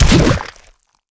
acidrocketfire.wav